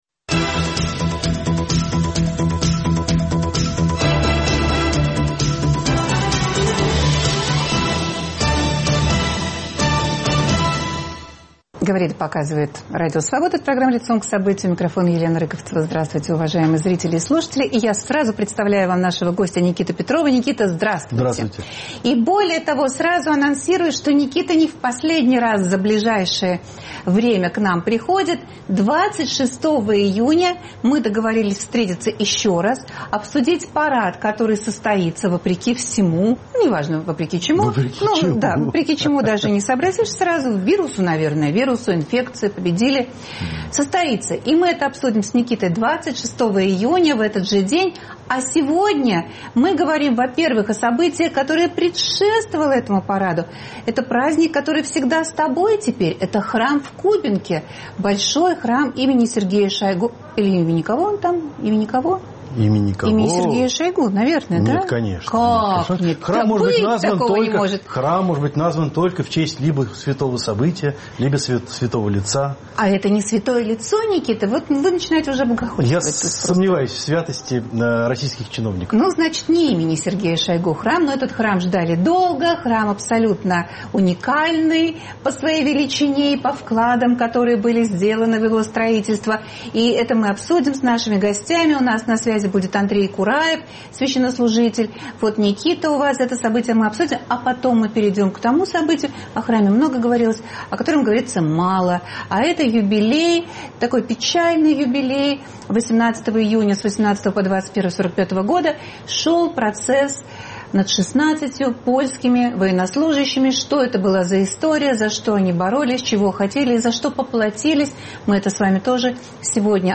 Вся картина церковно-партийной стройки в изложении протодиакона Андрея Кураева. А также: коварство Сталина и июньский процесс 45-го года над 16-тью польскими военнослужащими.